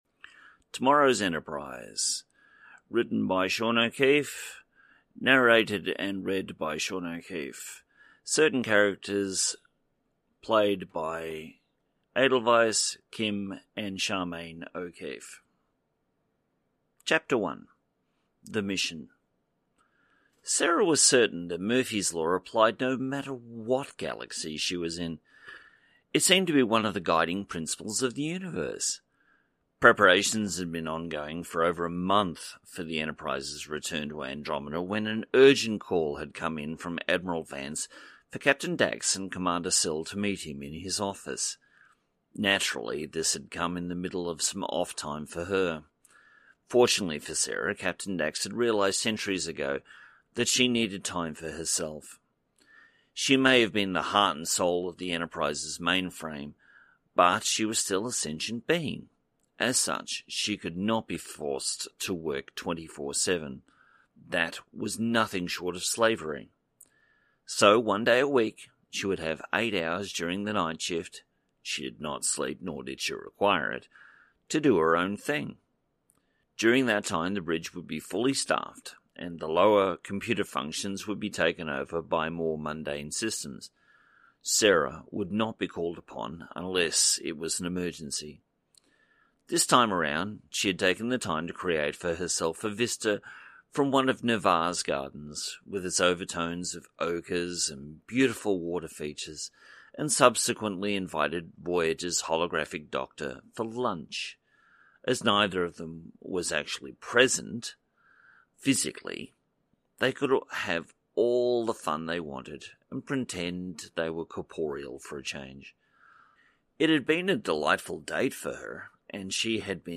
Audio Books/Drama